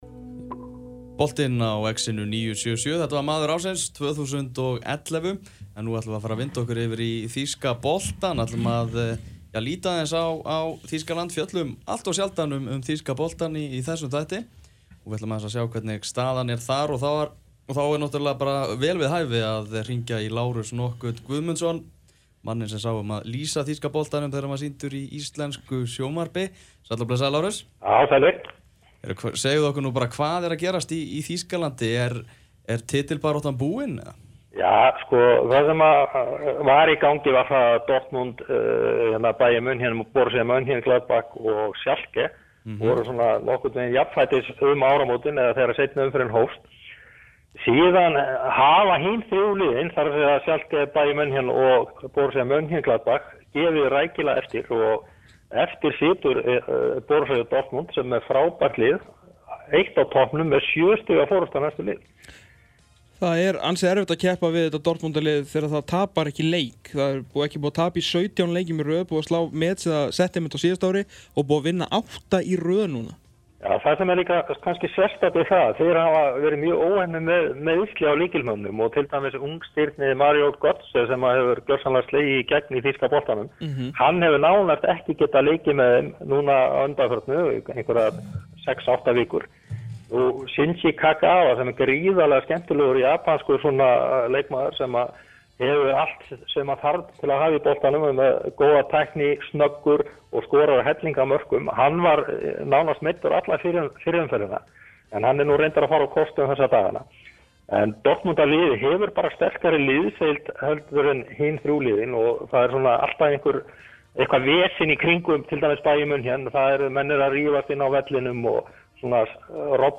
Boltinn var á dagskrá á X-inu FM 97,7 í dag en þar var rennt yfir gang mála í helstu deildum Evrópu.
Meðal annars var rætt um stöðu Gylfa Þórs Sigurðssonar hjá Hoffenheim, versnandi gengi FC Bayern og stemninguna á heimaleikjum Borussia Dortmund. Viðtalið